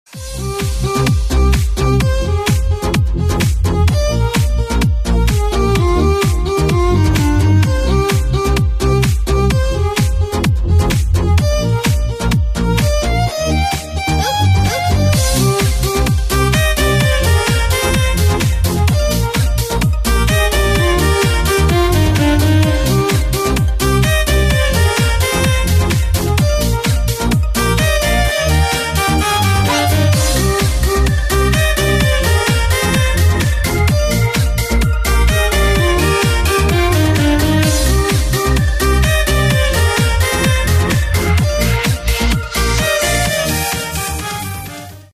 • Качество: 128, Stereo
громкие
dance
Electronic
EDM
электронная музыка
без слов
club
энергичные